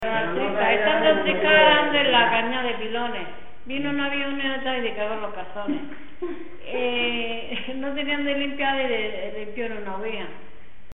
Materia / geográfico / evento: Canciones de carnaval Icono con lupa
Moraleda de Zafayona (Granada) Icono con lupa
Secciones - Biblioteca de Voces - Cultura oral